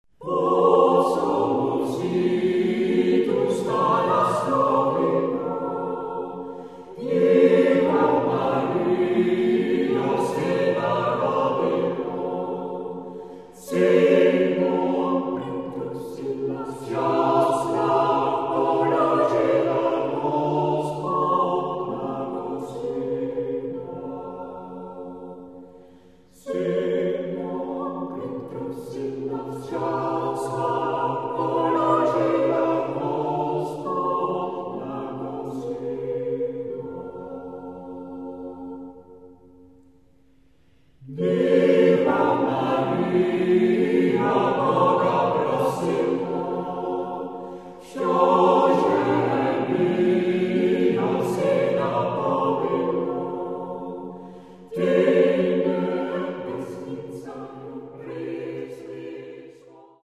Tonart(en): G-Dur